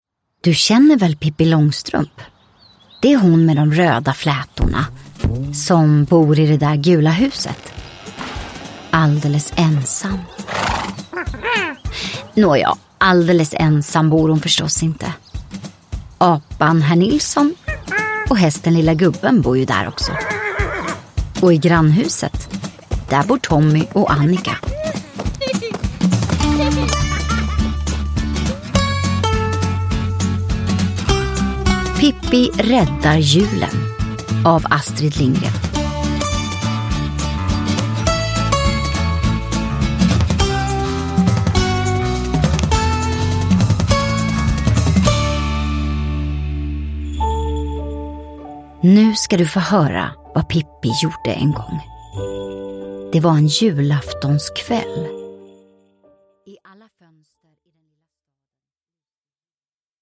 Pippi räddar julen (Ljudsaga) – Ljudbok